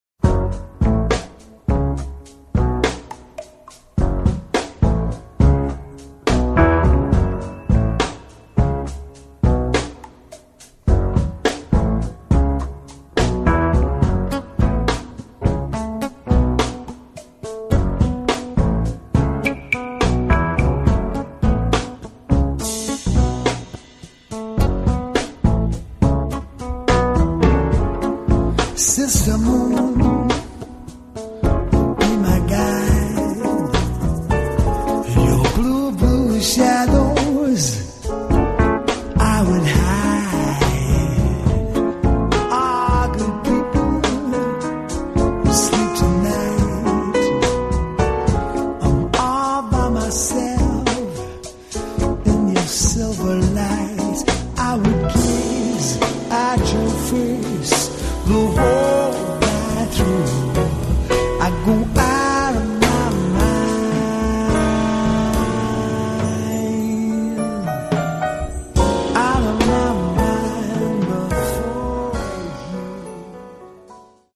Organ, Piano, Keyboards